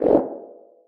Sfx_creature_penguin_waddle_voice_03.ogg